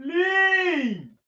plim.wav